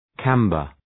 Προφορά
{‘kæmbər}